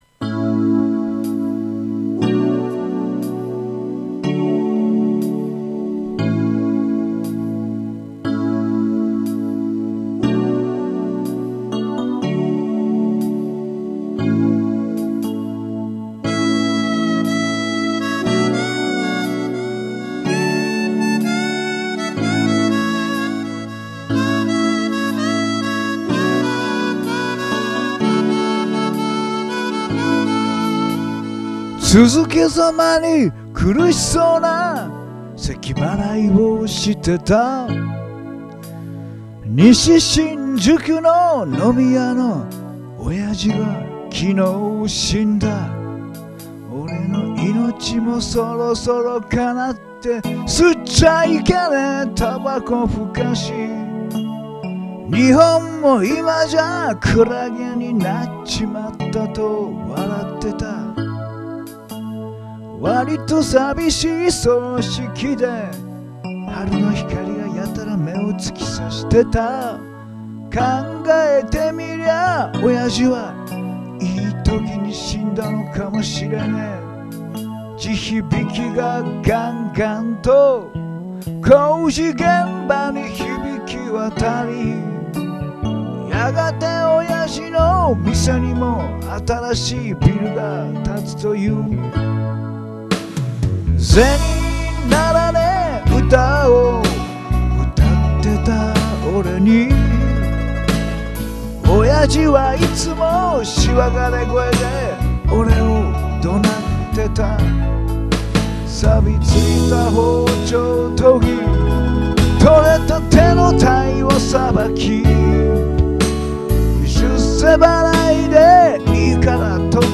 JOU SOUND でホームカラオケしたものを録音いたしまして、